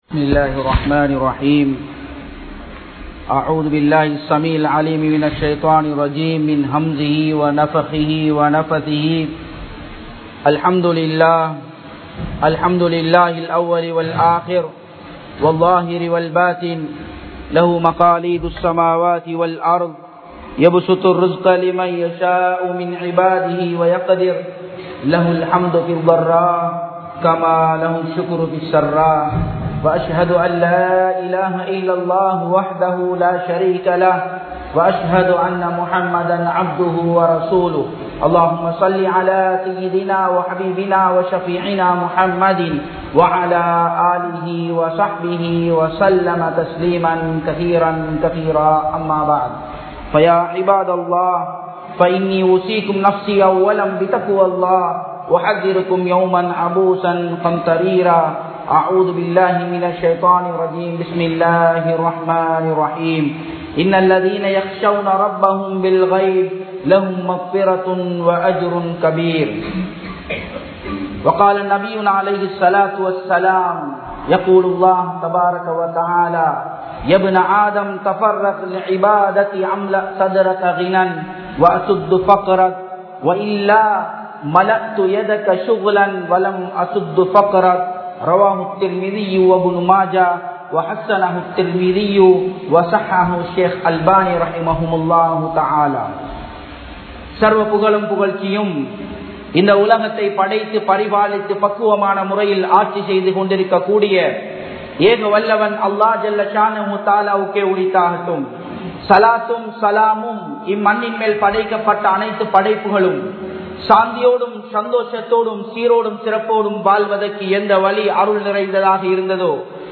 Vidumuraihalai Evvaaru Kalippathu? (விடுமுறைகளை எவ்வாறு கழிப்பது?) | Audio Bayans | All Ceylon Muslim Youth Community | Addalaichenai
Kotahena, Shoe Road Jumua Masjidh